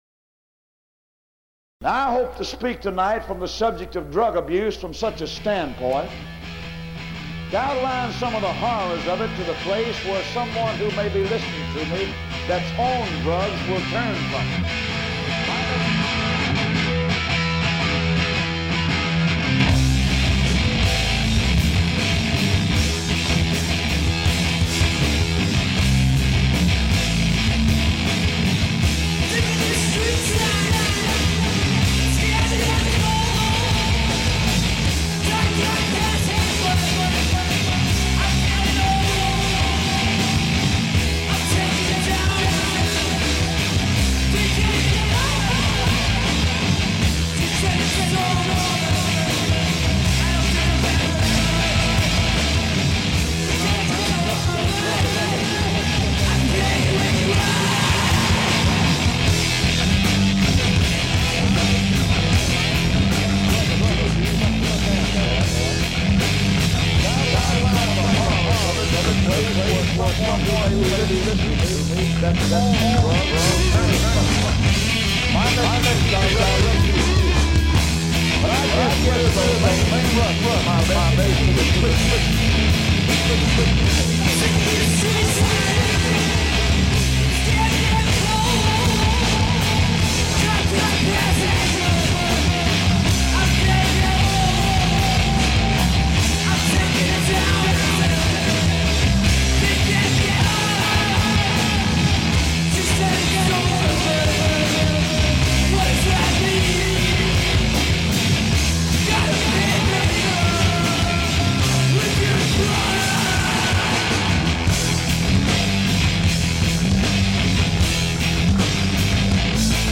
Eighteen-minute (21.2 MB) ape-mix for kicks …